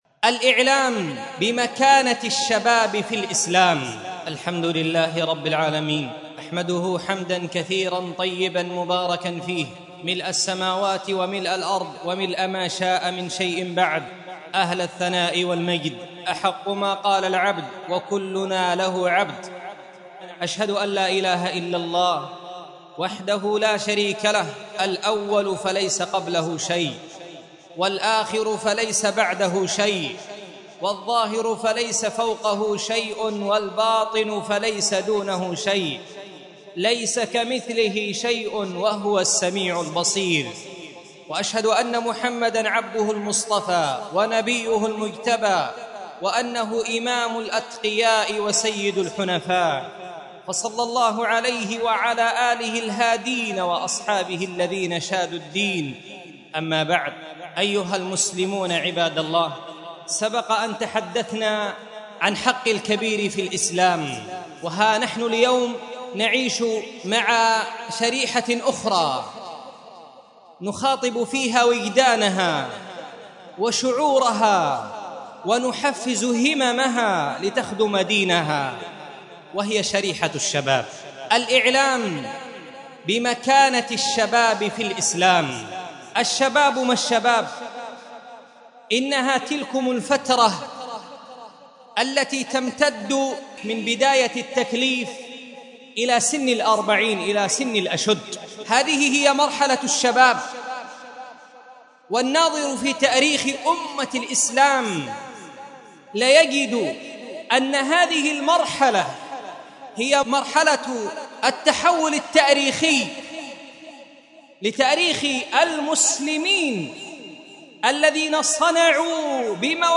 مسجد درة عن محافظة عدن حرسها الله